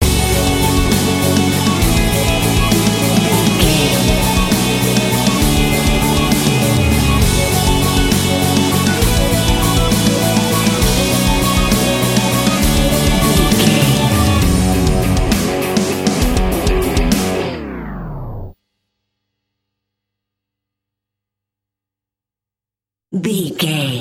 Aeolian/Minor
F#
drums
electric guitar
bass guitar
hard rock
lead guitar
aggressive
energetic
intense
nu metal
alternative metal